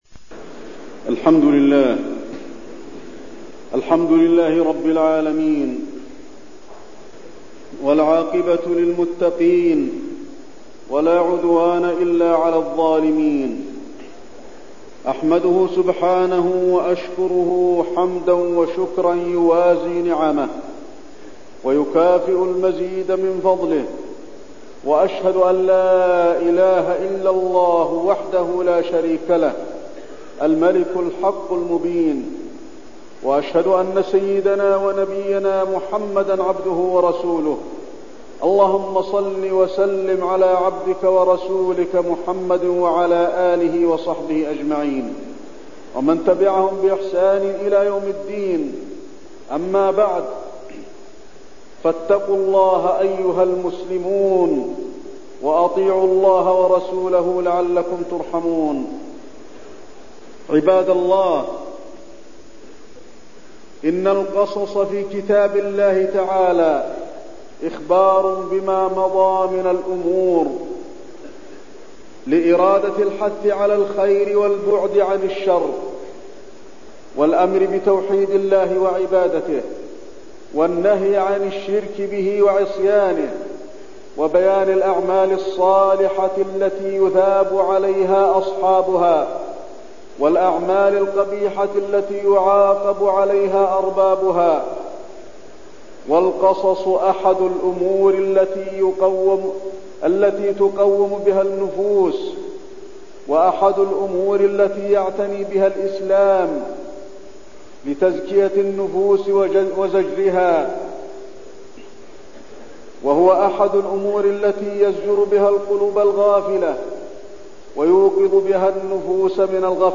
تاريخ النشر ٢٧ ذو الحجة ١٤٠٧ هـ المكان: المسجد النبوي الشيخ: فضيلة الشيخ د. علي بن عبدالرحمن الحذيفي فضيلة الشيخ د. علي بن عبدالرحمن الحذيفي قصص من القرآن